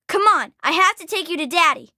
Category:Fallout 3 audio dialogues Du kannst diese Datei nicht überschreiben. Dateiverwendung Die folgende Seite verwendet diese Datei: Rachael (Fallout 3) Metadaten Diese Datei enthält weitere Informationen, die in der Regel von der Digitalkamera oder dem verwendeten Scanner stammen.